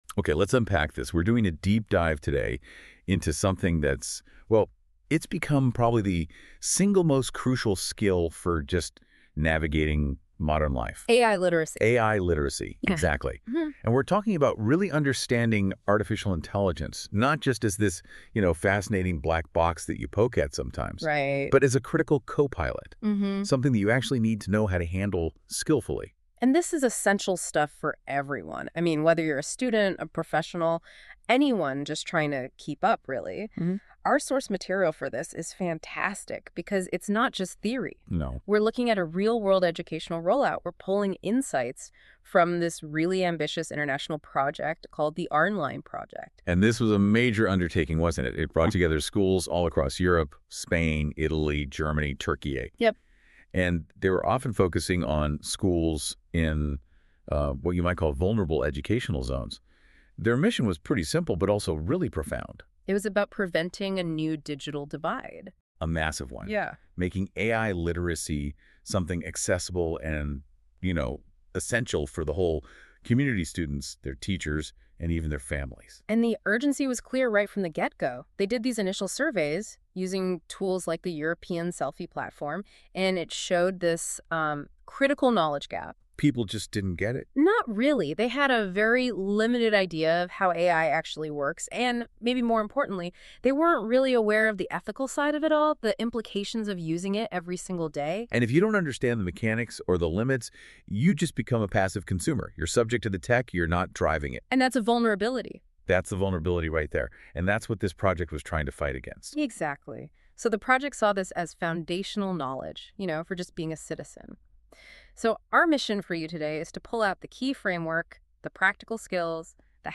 A podcast created by AI about our project – An Essential Digital Competency: AI Literacy
AI-created-ARINLI-podcast.mp3